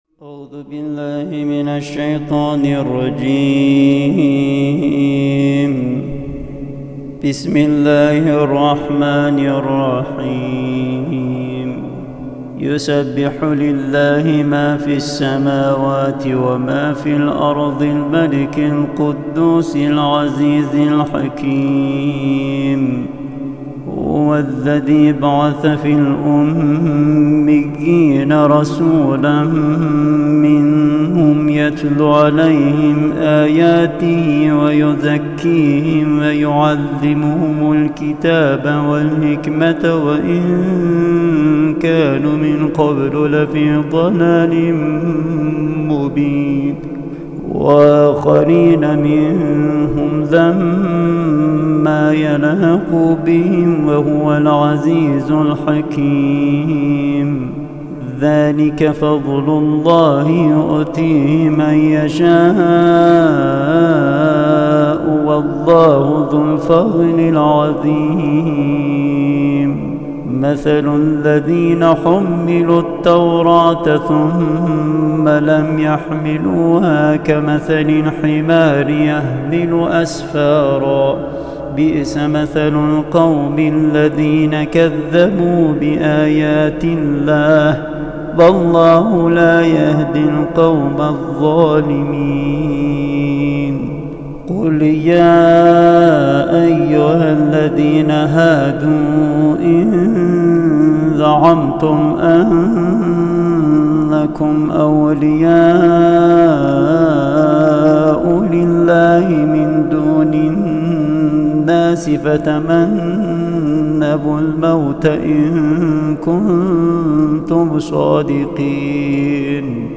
قرائت سوره مبارکه جمعه